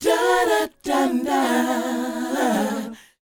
DOWOP F#4D.wav